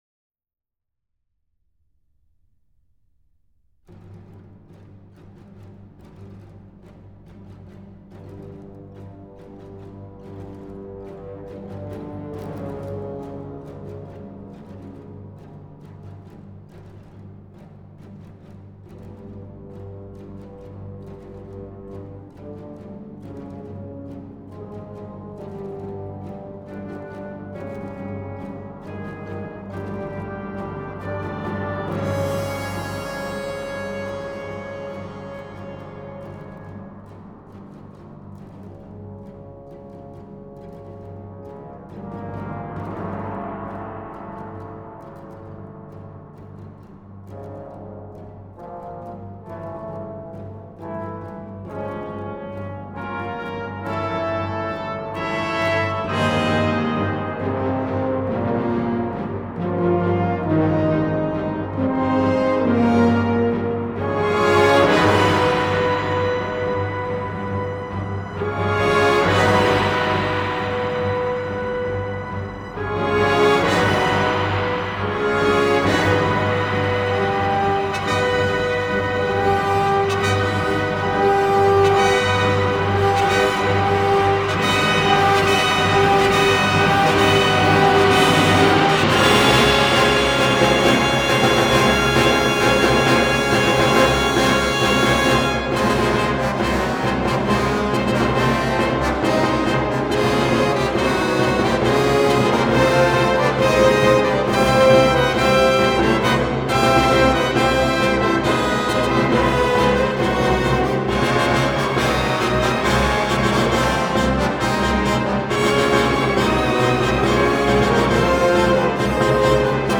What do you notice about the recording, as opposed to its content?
I can only provide a traditional recording of the original arrangement, but imagine all string parts substituted by flutes and woodwinds, with extra heaps of added brass, all playing to the hilt, and you will get a sense of what I considered the highlight of an evening which had many.